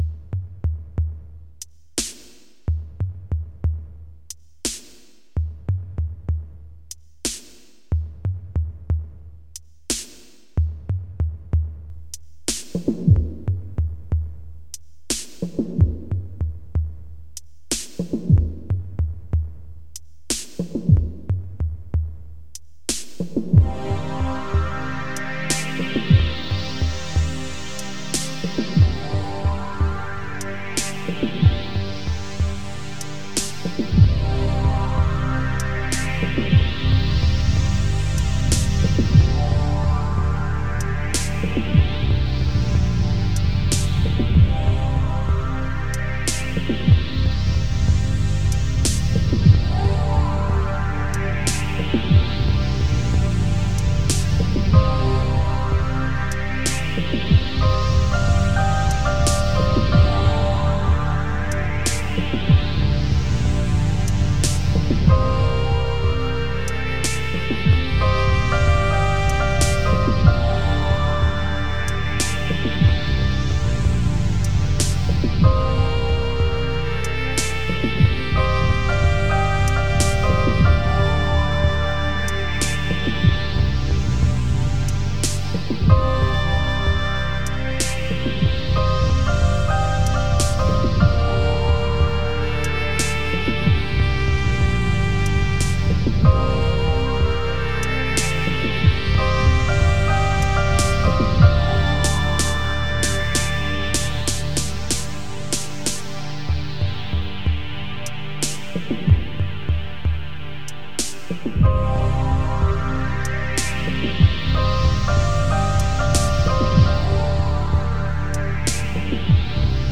UFO Engine Type 1 (Retro Sci-Fi Sound Effects).wav